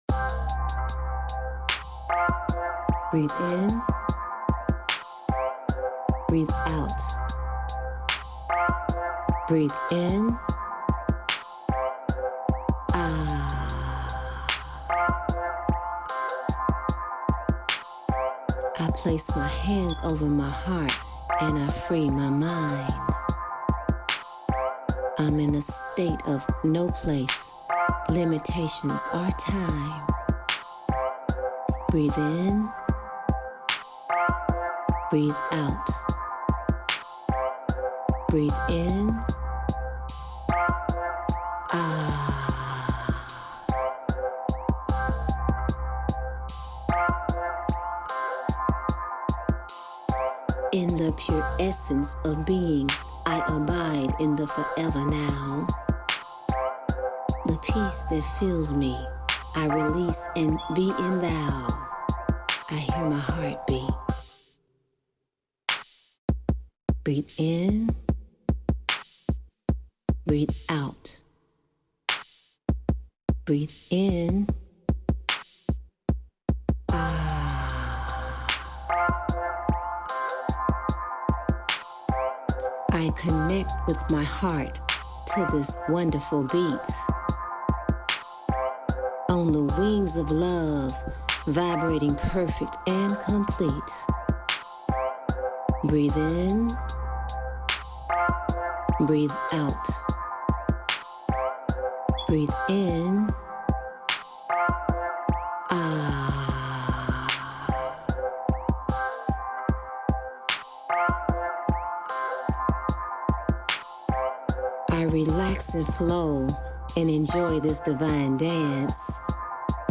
Brain Entrainment Tracks
528 Hertz frequency
Alpha wave Binaural Beats (for headphones)
Ah Mantras (heart mantra)